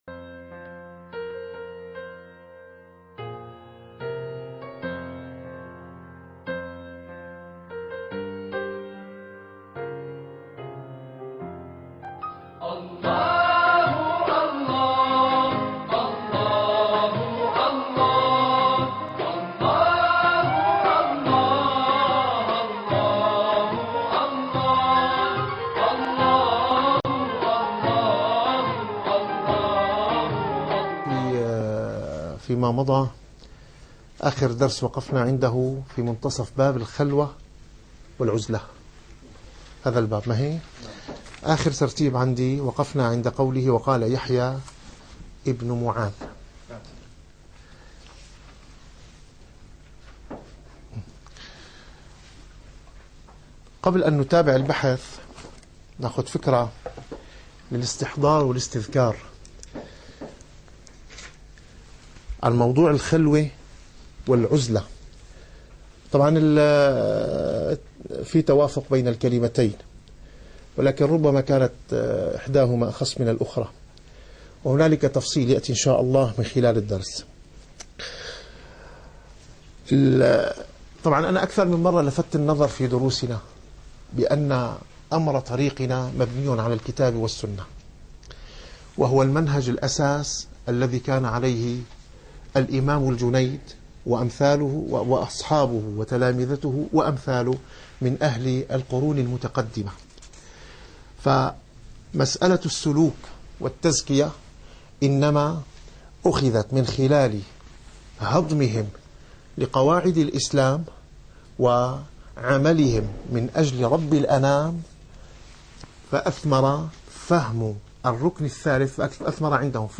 - الدروس العلمية - الرسالة القشيرية - الرسالة القشيرية / الدرس السادس والثلاثون.